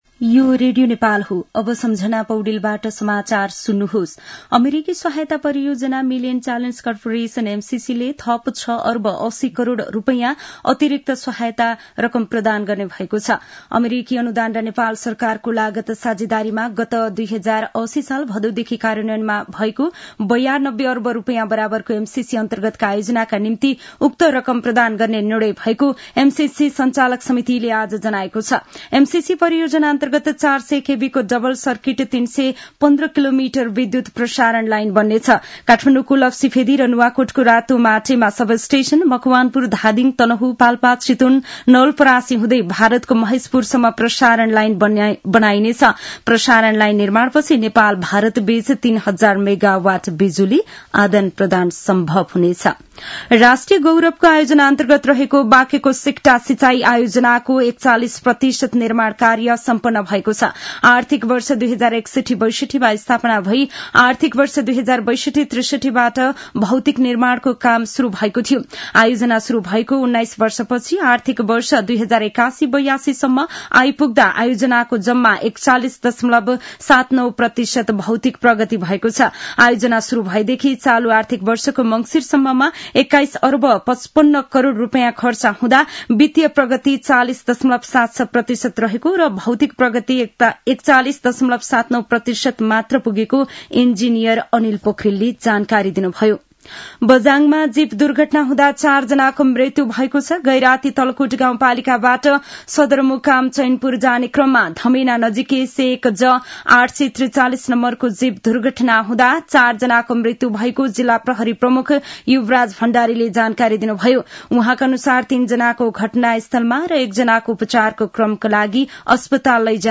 An online outlet of Nepal's national radio broadcaster
दिउँसो १ बजेको नेपाली समाचार : २० पुष , २०८१
1-pm-nepali-news-1-2.mp3